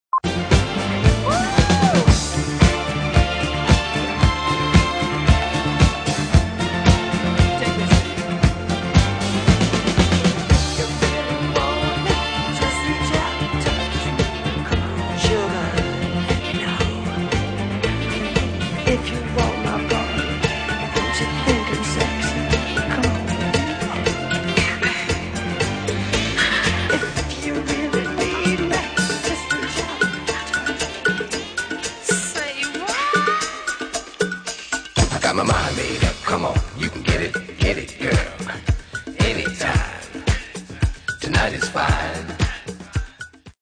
demos...